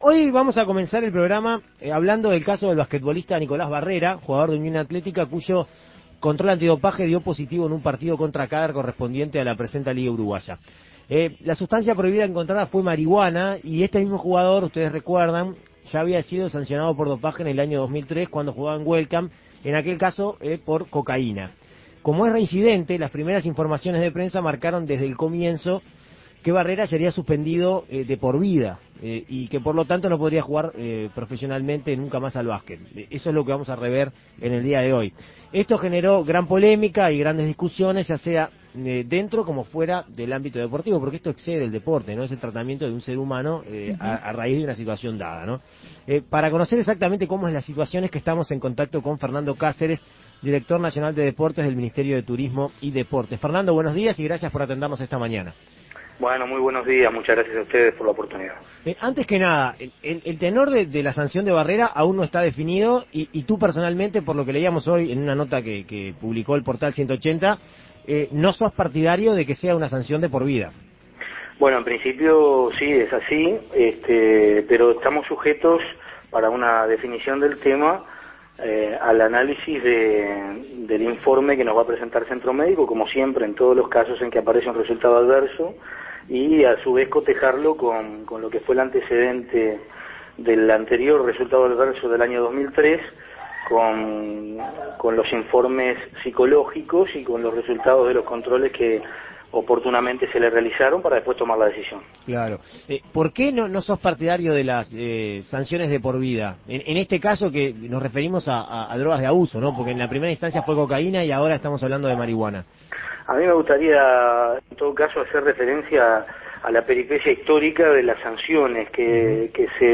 Para responder a esta y otras interrogantes, En Perspectiva Segunda Mañana dialogó con Fernando Cáceres, director nacional de Deportes del Ministerio de Turismo y Deportes.